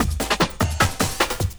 50 LOOP10 -R.wav